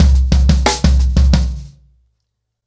Wireless-90BPM.27.wav